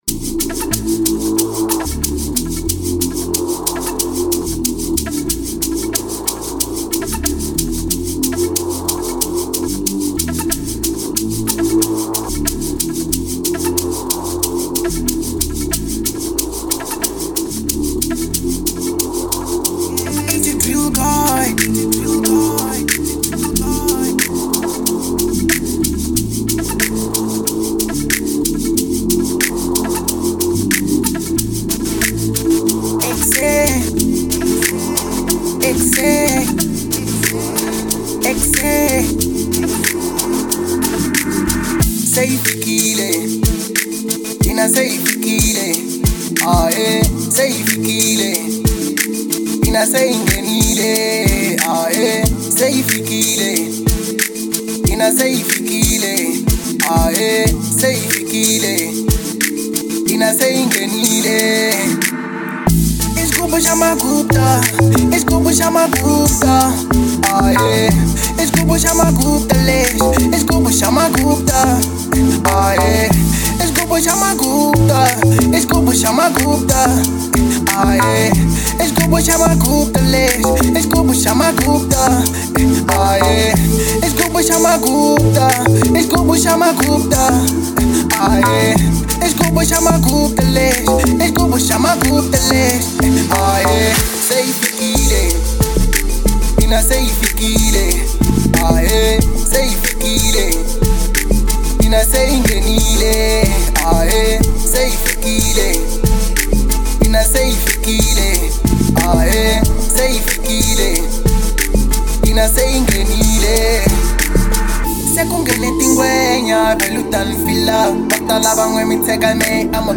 03:43 Genre : Amapiano Size